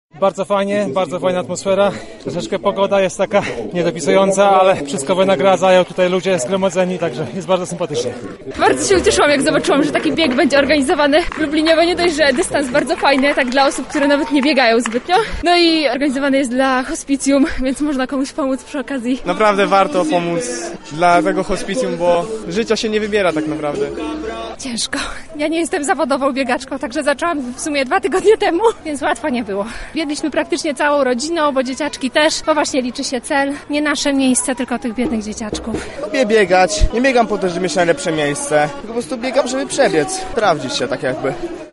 Wrażeniami z imprezy podzielili się sami uczestnicy.
Sonda-hospicjum.mp3